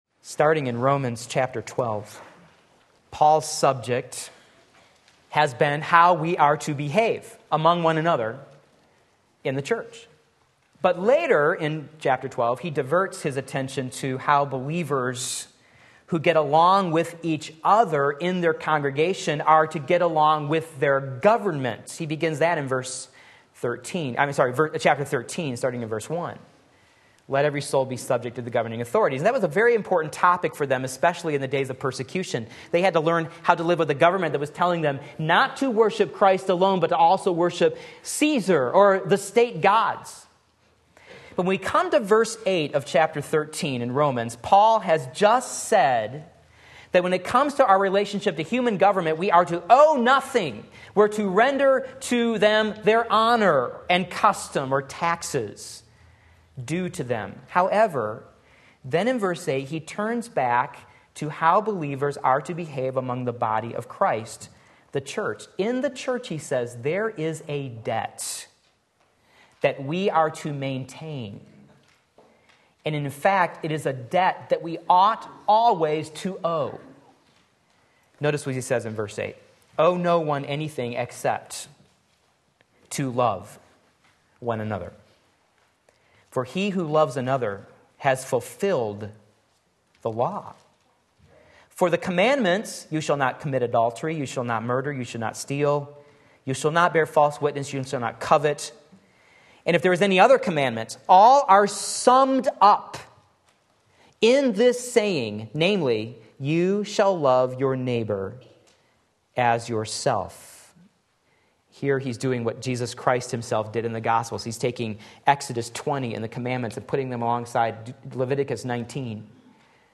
Sermon Link
Loving One Another in the Church Romans 13:8-10 Sunday Morning Service